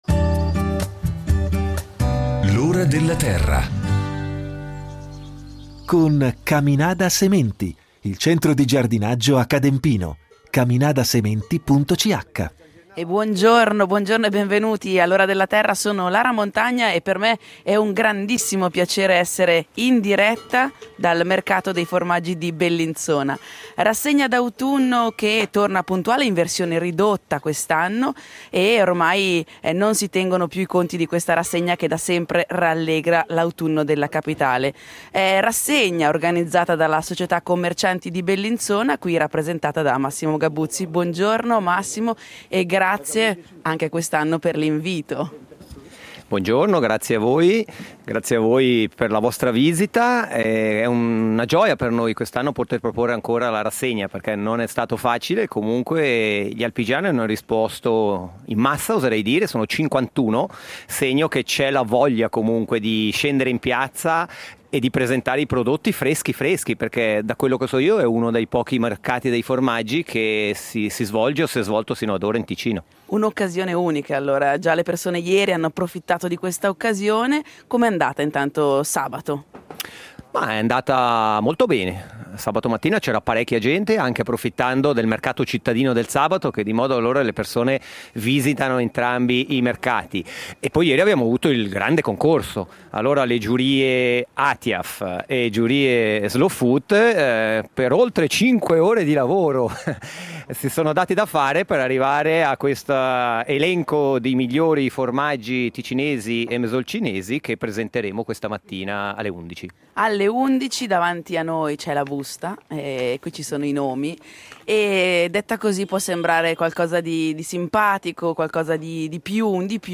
Vi proporremo un reportage realizzato al corte principale del Giumello, a 1600 m s.l.m. dove all’inizio del secolo scorso passavano i contrabbandieri, con le bricolle colme di riso, pasta, salumi, stoffe, tappeti e nello stesso tempo anche caffè, sale e sigarette.